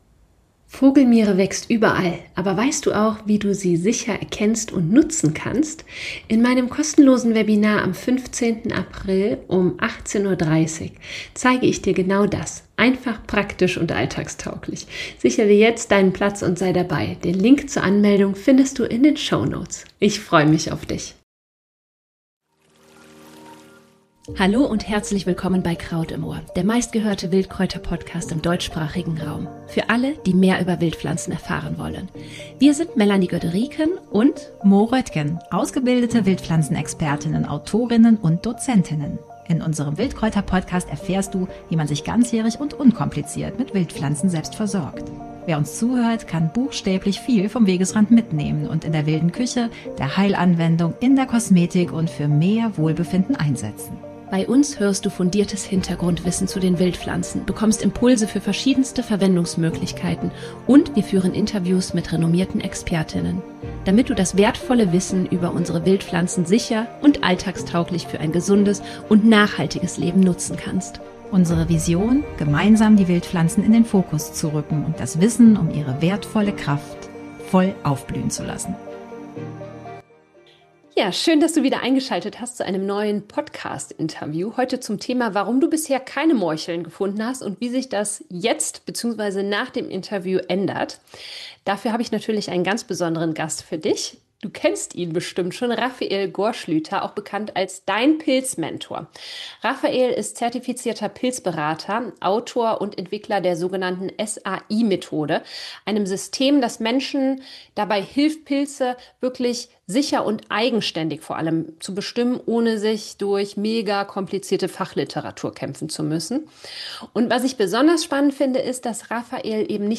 Kraut-Interview: Morcheln im Frühling sammeln.